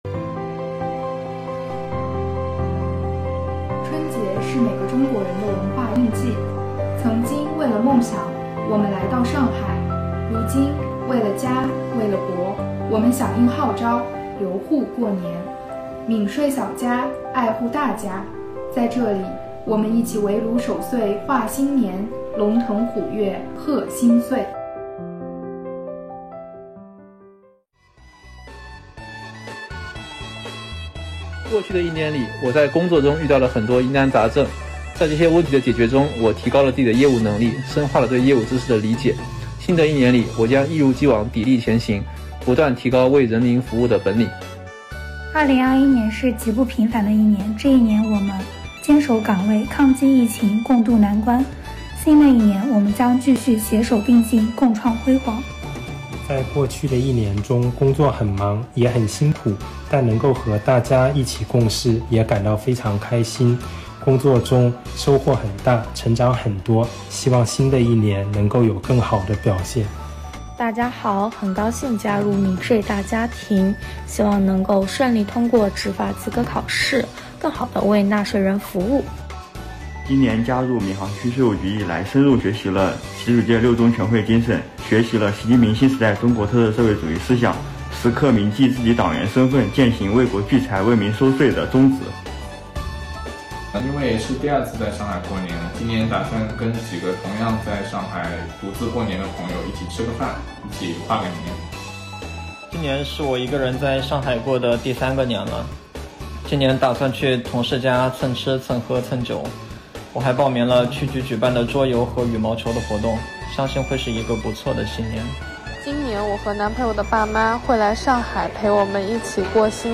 1月26日，下着小雨的傍晚，来自各个部门的税务青年们结束了一天忙碌的工作，欢聚一堂，参加闵行区税务局“情暖税月，花样年华”新春联欢会。寒冷的天气也挡不住青年们的热情，联欢会在青年干部们的祝福视频中拉开序幕。